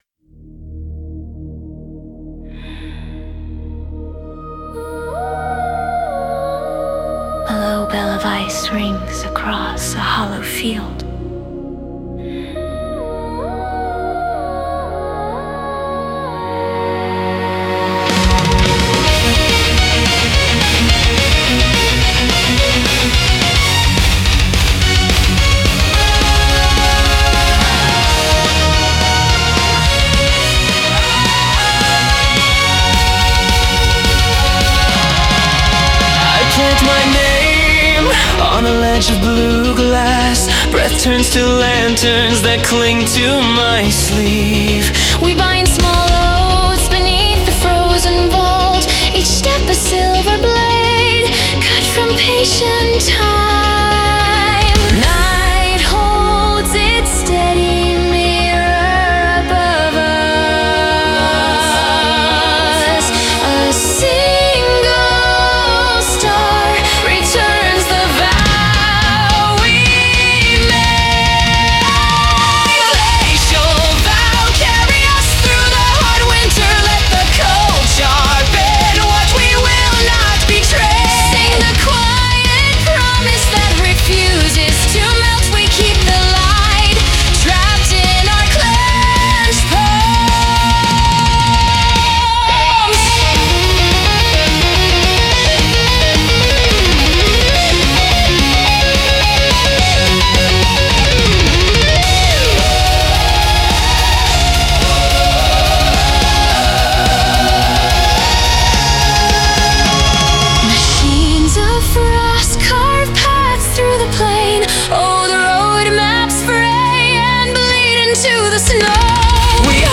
Symphonic Metal